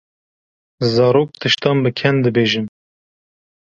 Pronunciado como (IPA) /kɛn/